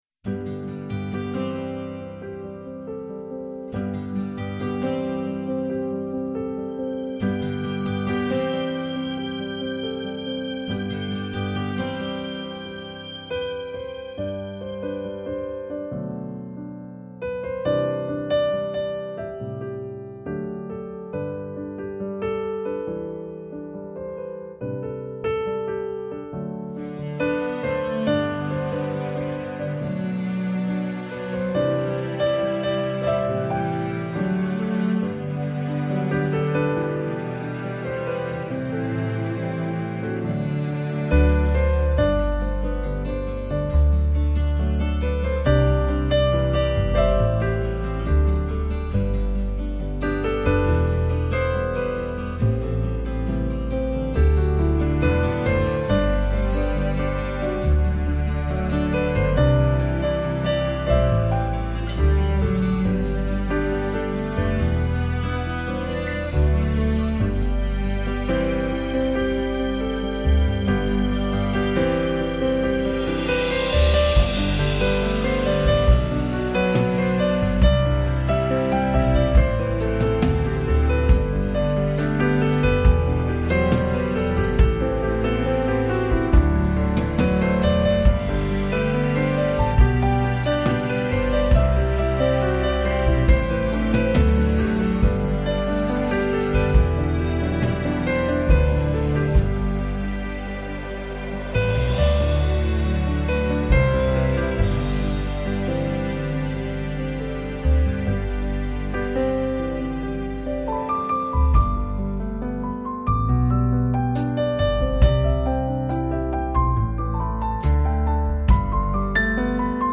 音乐流派: Piano, Instrumental, Easy Listening
这琴梦幻淡淡的开始，如此的清新，但总会轻易的打动你的心灵。